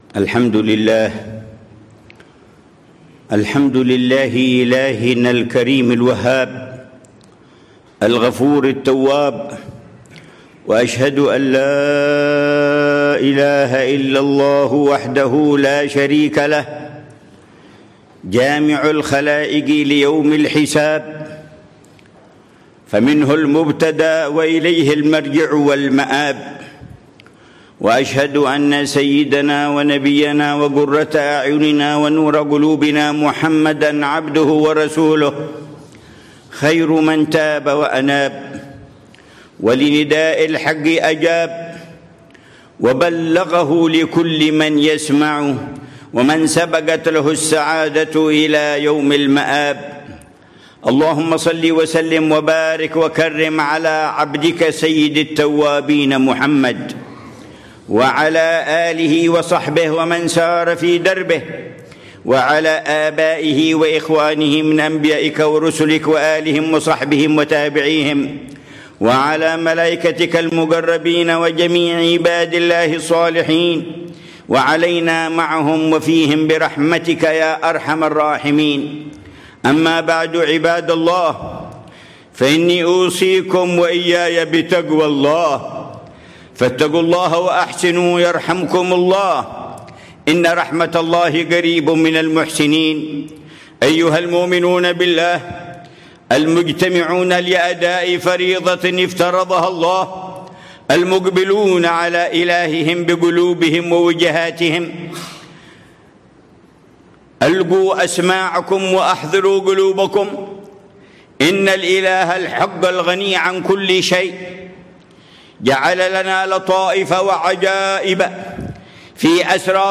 خطبة الجمعة للعلامة الحبيب عمر بن محمد بن حفيظ، في مسجد الشريفة فاطمة بنت ناصر، في عمّان، الأردن، 21 صفر الخير 1447هـ بعنوان: